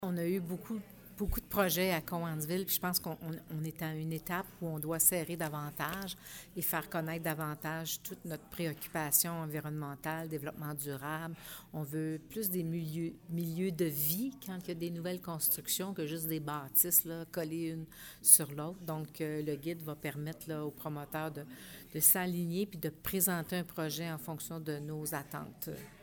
La mairesse de Cowansville, Sylvie Beauregard, explique que le projet à pour but d’apporter une certaine harmonie dans le développement de la ville :